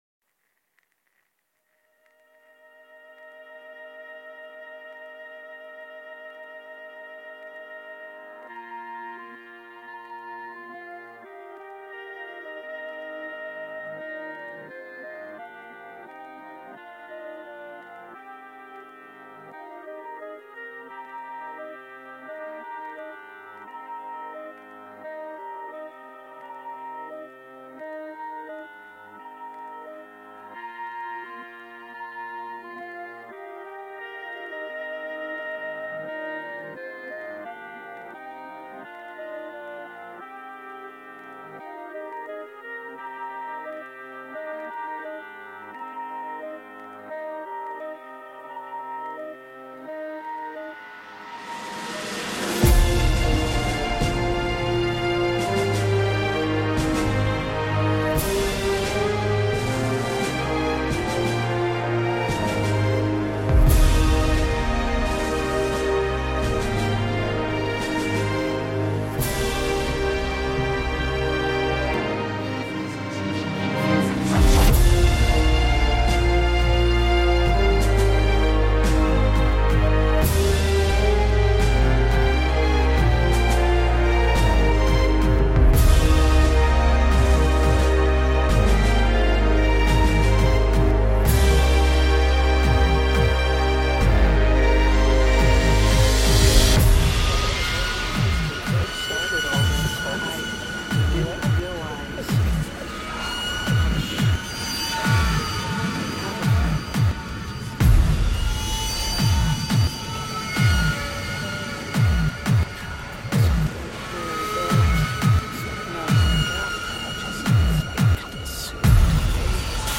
Guitar, Vocals, Bass, Programming